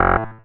sci-fi_code_fail_06.wav